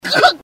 Laugh 5